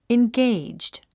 発音
ingéidʒd　インゲェイジド